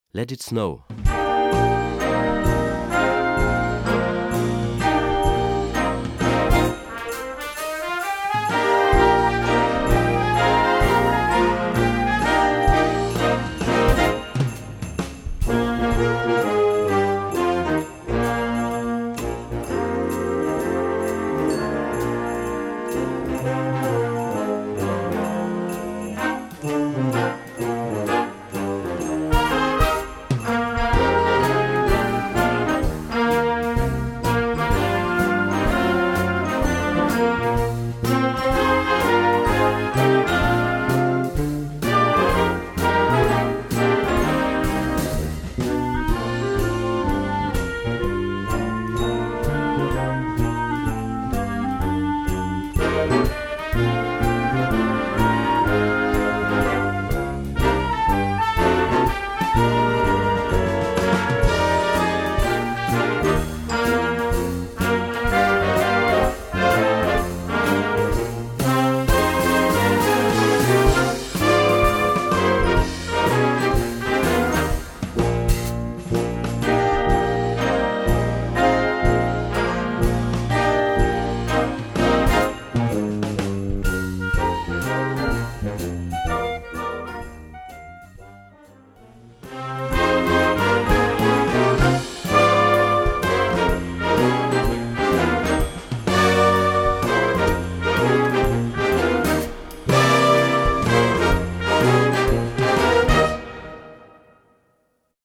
Gattung: Weihnachtsmusik für Jugendblasorchester
Besetzung: Blasorchester
1. Stimme: Klarinette/Flügelhorn/Es-Klarinette
3. Stimme: Trompete/Flöte - Lead Part!
6. Stimme: Posaune/Euphonium/Tenorsaxophon/Bassklarinette